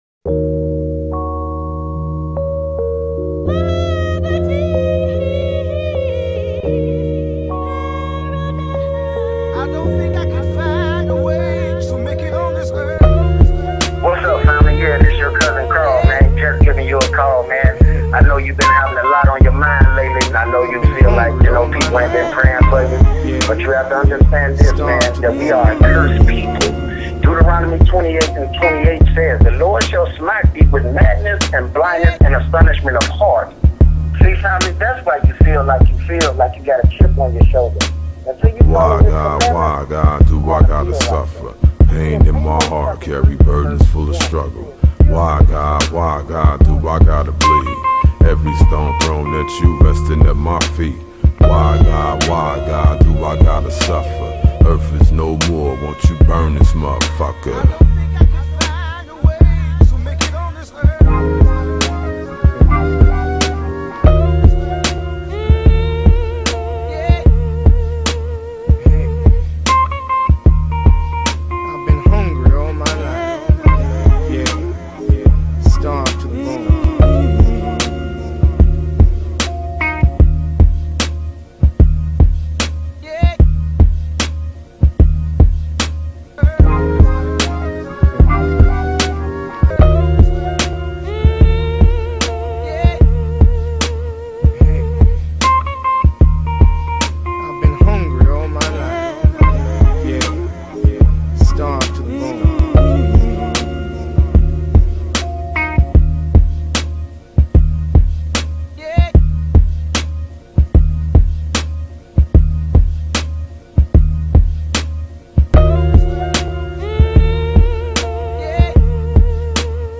fear_instrumental.mp3